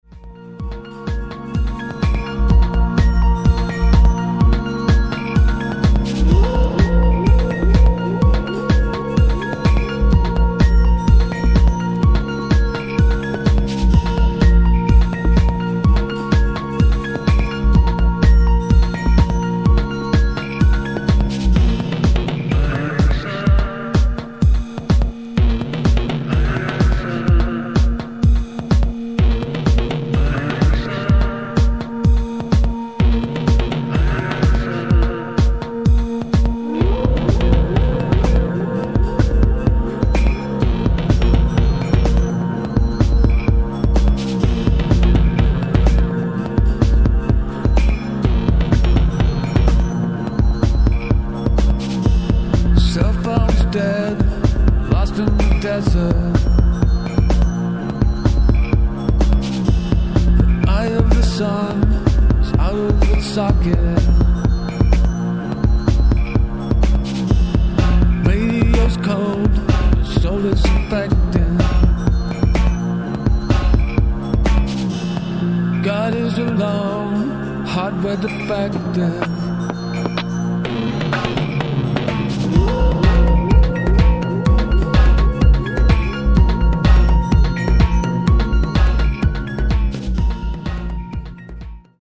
Techno (minimal)mixes
House Techno